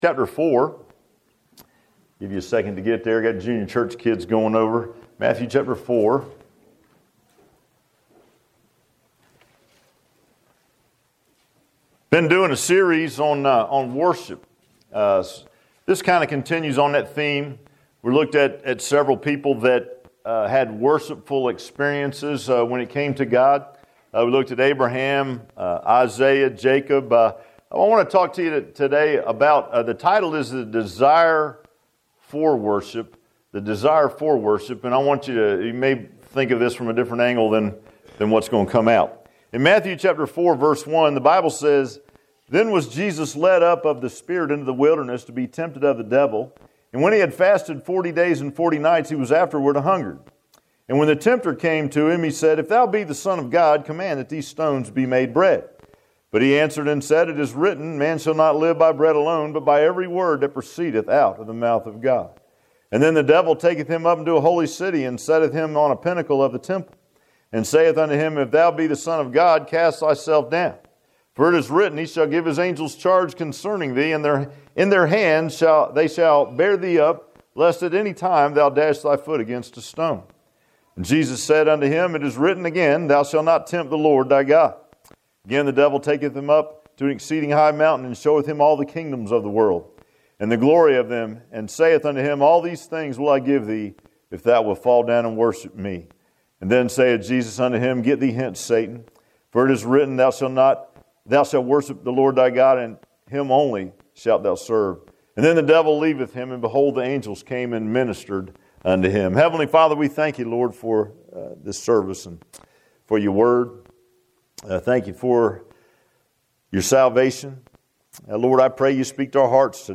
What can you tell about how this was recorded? Matthew 4:1-11 Service Type: Sunday AM Bible Text